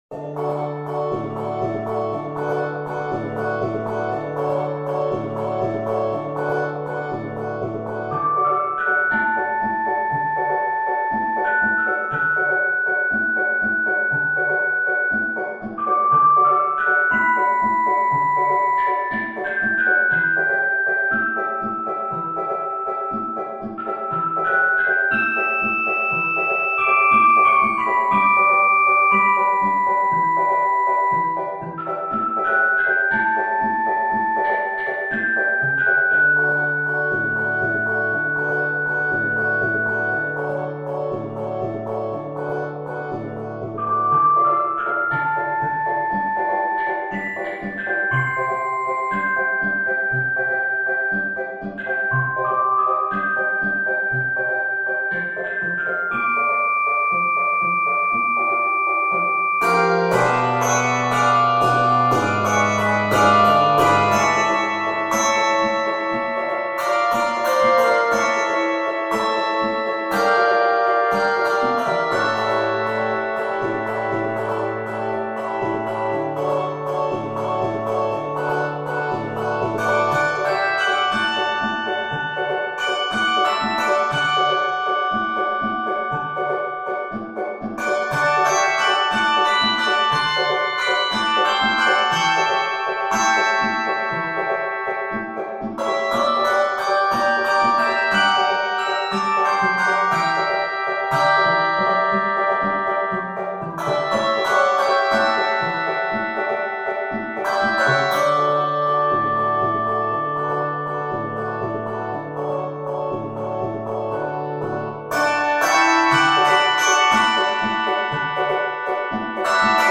bells and chimes
Key of D Major.